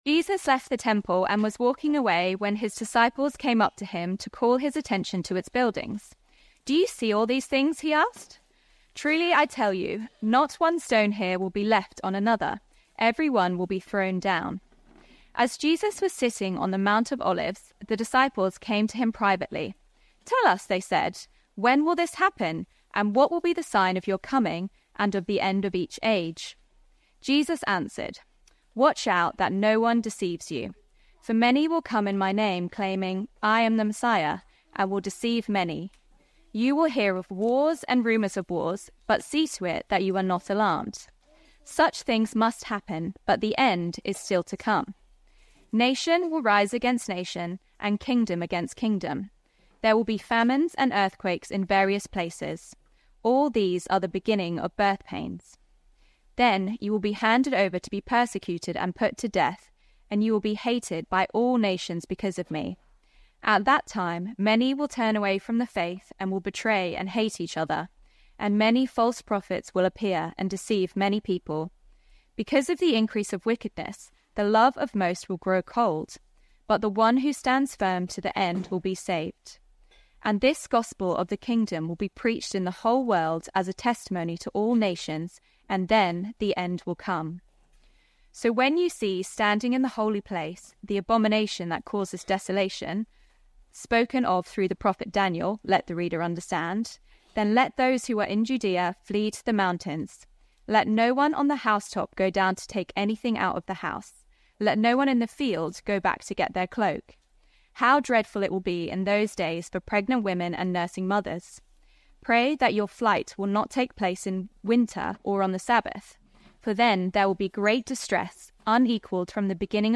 This is a one-off sermon on the start of Matthew 24.